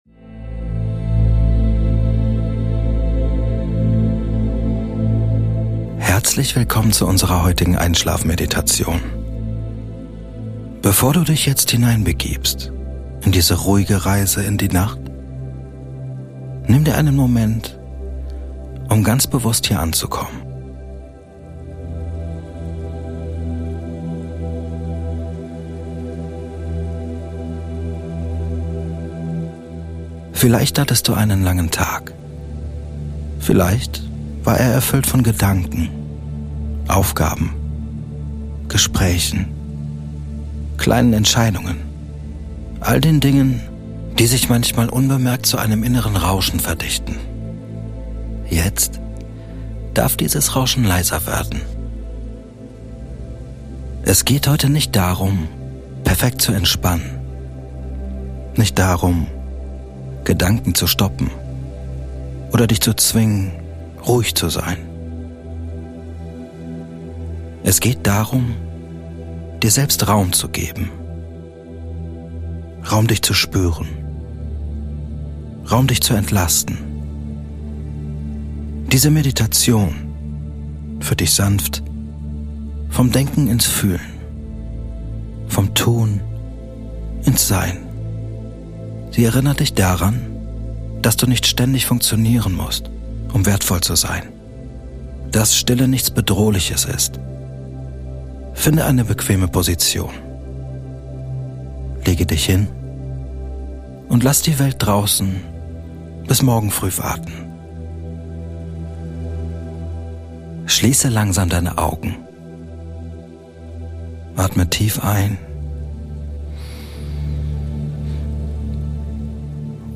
Diese geführte Einschlafmeditation hilft dir, nach einem langen Tag zur Stille zu finden, Anspannung loszulassen und den Geist zu beruhigen. Du wirst spüren, wie dein Atem ruhiger wird, der Körper weicher, und die Gedanken langsam verblassen.
Sie ist ruhig gesprochen, psychologisch fundiert und wirkt wie ein sanftes Ritual für deine Abendroutine – ohne Druck, ohne Erwartungen.